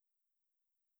blank.wav